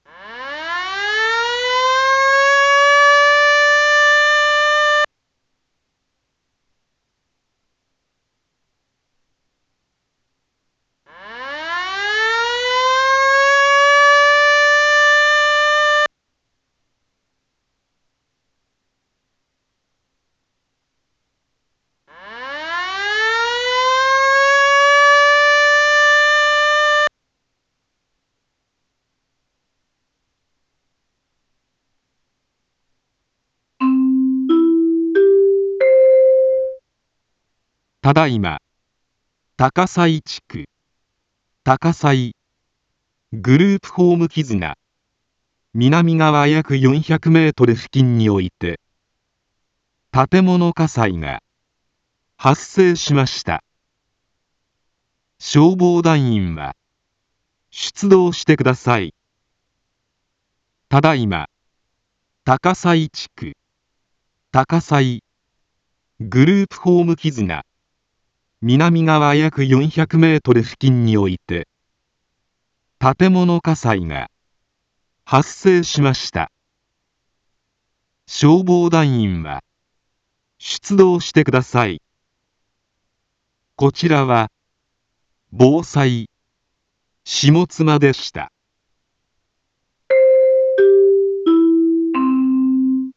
一般放送情報
Back Home 一般放送情報 音声放送 再生 一般放送情報 登録日時：2024-07-25 19:50:37 タイトル：火災報 インフォメーション：ただいま、高道祖地区、高道祖、グループホーム絆南側約400メートル 付近において、 建物火災が、発生しました。